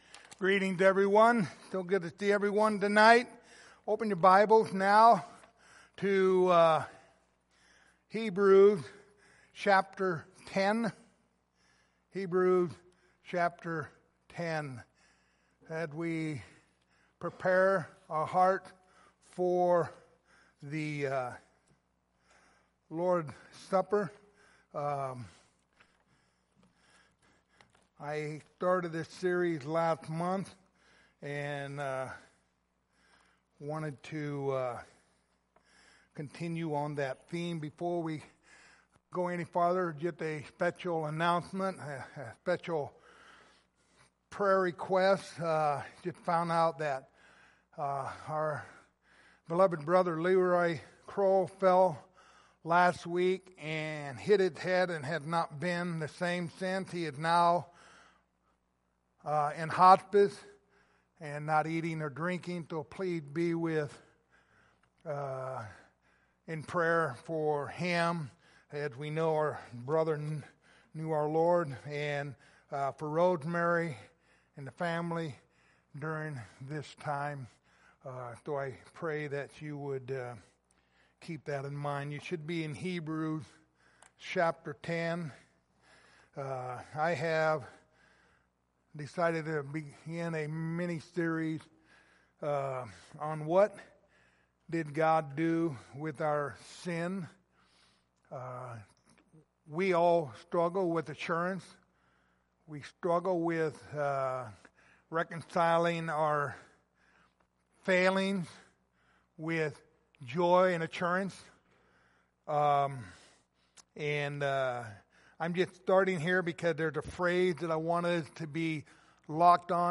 Lord's Supper Passage: Hebrews 10:19-23 Service Type: Lord's Supper Topics